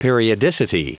Prononciation du mot periodicity en anglais (fichier audio)
Prononciation du mot : periodicity